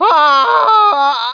1 channel
scream3.mp3